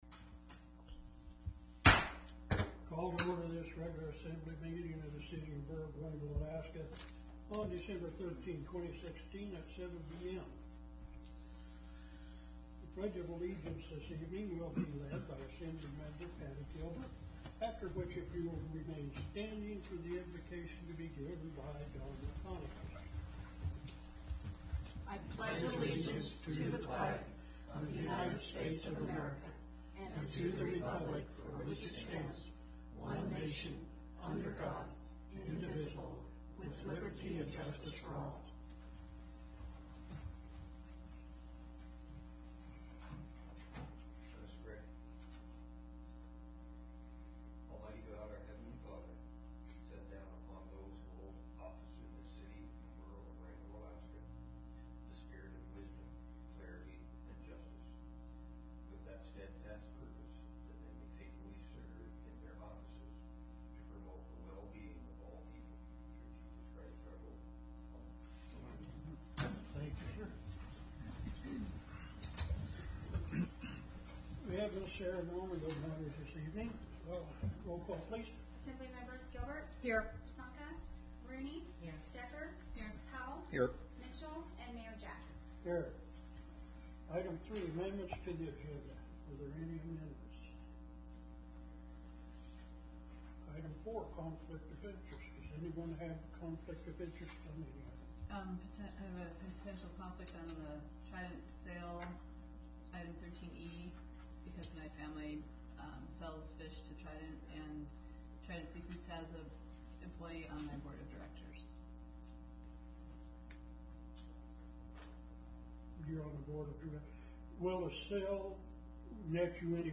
Borough Assembly Meeting Agenda